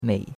mei3.mp3